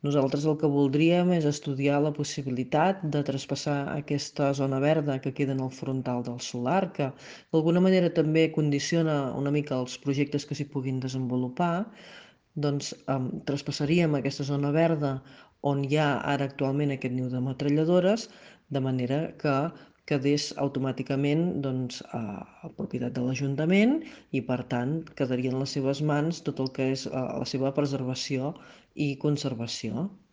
Montse Rovira, regidora d’urbanisme de Castell Platja d’Aro, ha explicat a Ràdio Capital que amb aquest suspens de llicències aprofitaran per estudiar casuístiques per protegir aquest niu de metralladores.